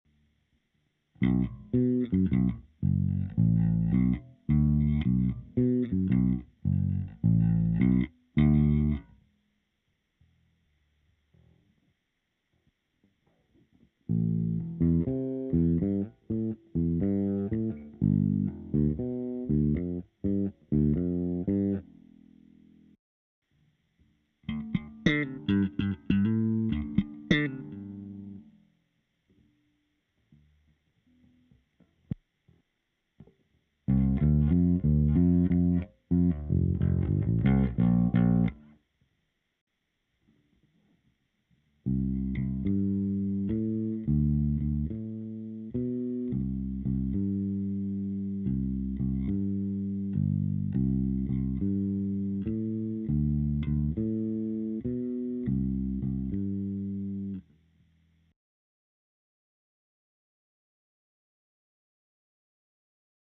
A zvukově moc pěkný na prsty, na ten slap moc ne, ale to může bej jen o nastavení.
To bude tim, ze struny uz byly stare a navic slap moc neumim.